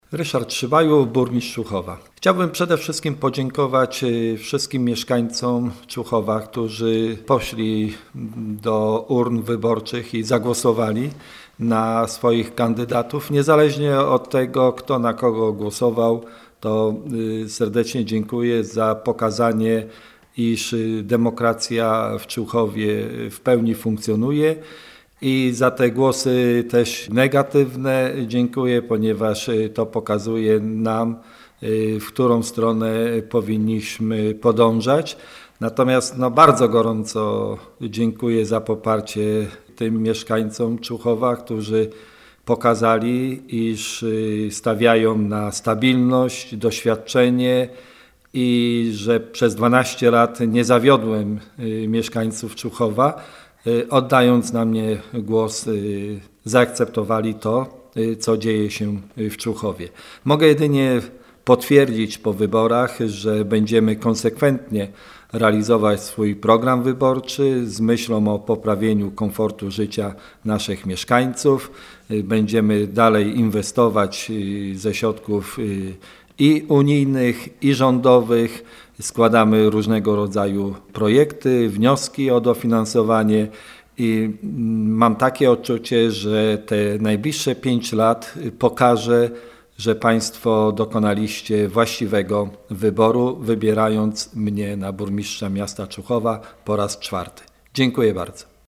- Chciałbym gorąco podziękować wszystkim mieszkańcom Człuchowa, którzy wzięli udział w tegorocznych wyborach samorządowych. Dziękuję za głosy oddane na mnie oraz za głosy negatywne. To pokazuje nam, w którą stronę powinniśmy podążać – mówi Ryszard Szybajło, Burmistrz Człuchowa.
00mc-wyborykomentarzburm.mp3